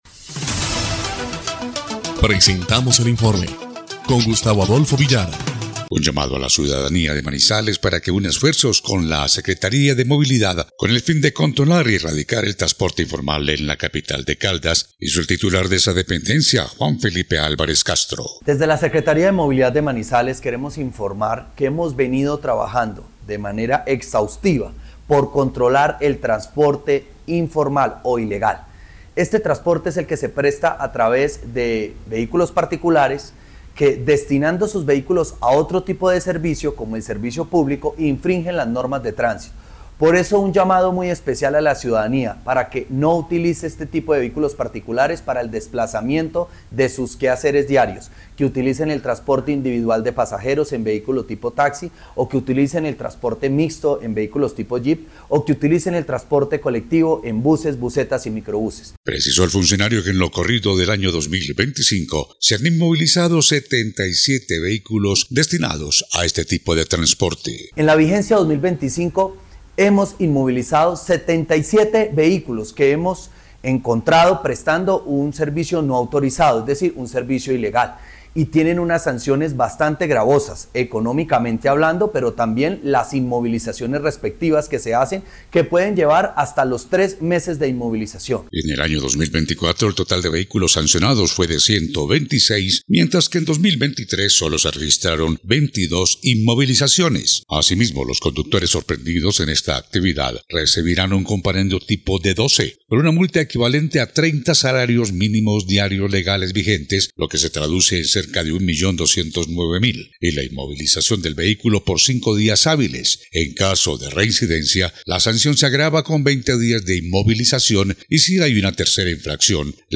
EL INFORME 3° Clip de Noticias del 26 de marzo de 2025